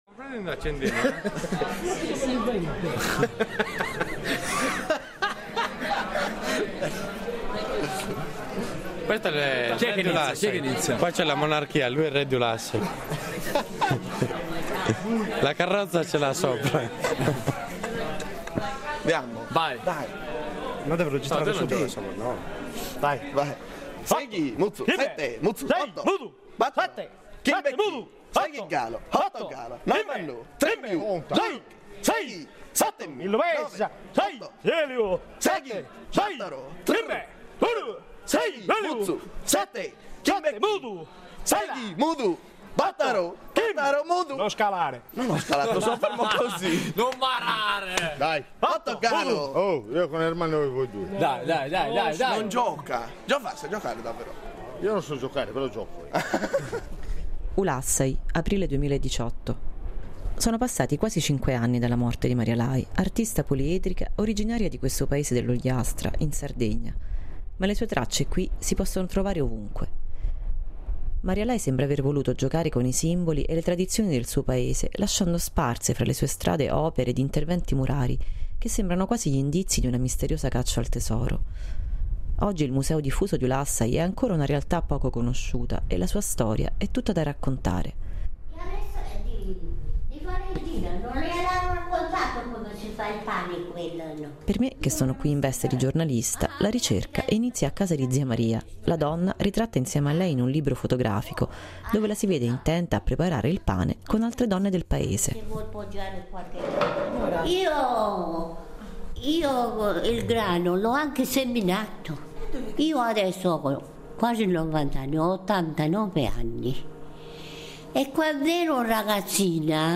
Oggi il museo diffuso di Ulassai è ancora una realtà poco conosciuta e la sua storia è tutta da raccontare. Seguendo un itinerario immaginario creato dalle voci degli ulassesi le opere dell’artista prendono vita e raccontano la storia della loro nascita.